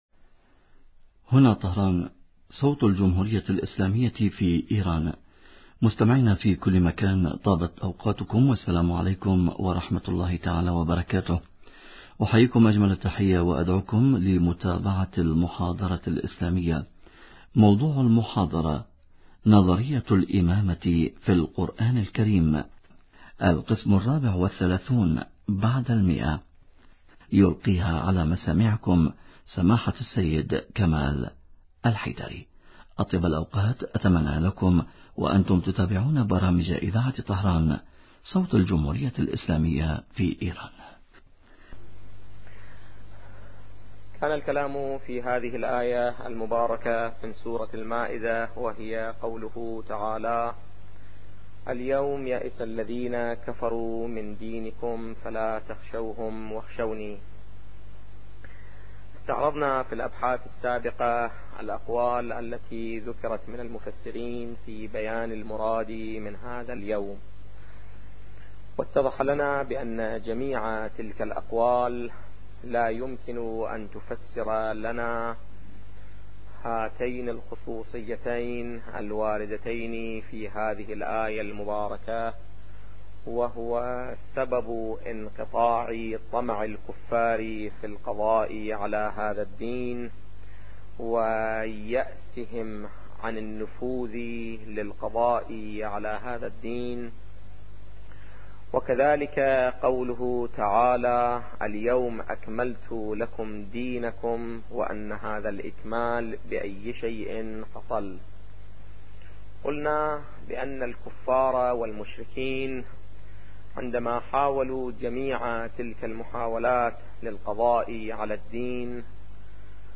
نظرية الامامة في القران الكريم - الدرس الرابع والثلاثون بعد المئة